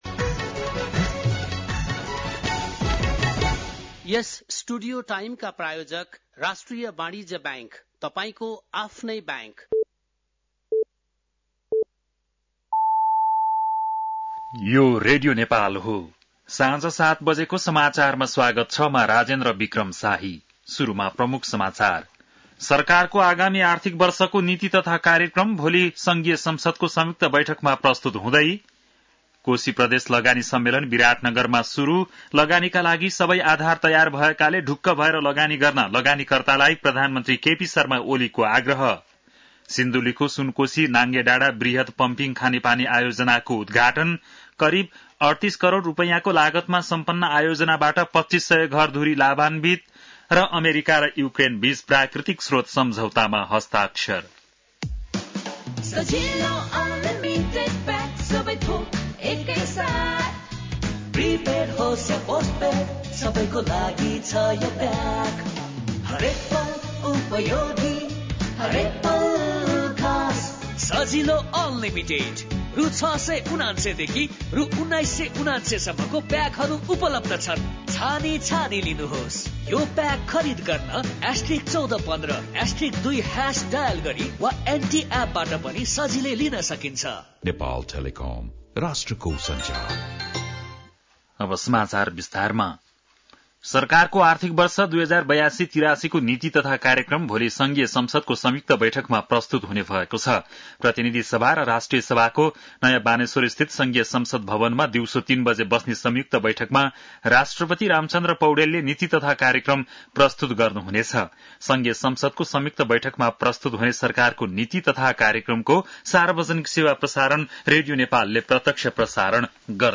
बेलुकी ७ बजेको नेपाली समाचार : १८ वैशाख , २०८२
7.-pm-nepali-news.mp3